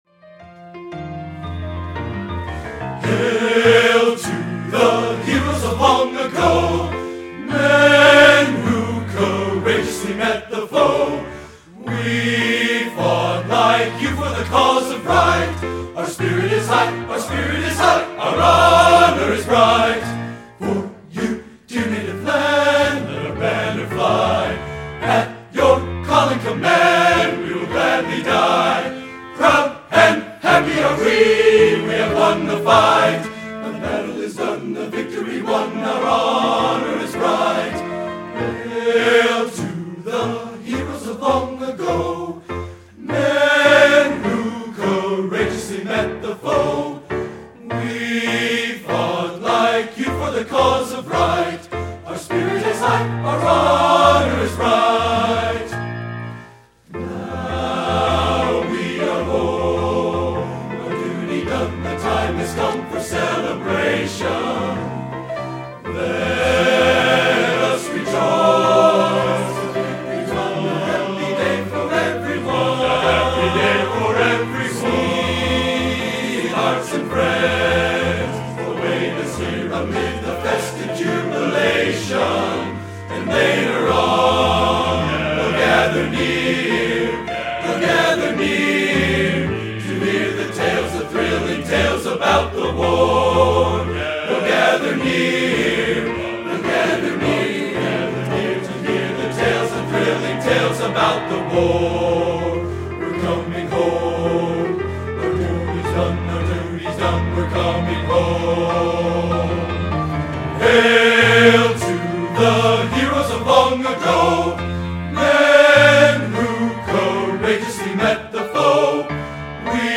Collection: Northrop High School 10/21/2001
Location: Northrop High School, Fort Wayne, Indiana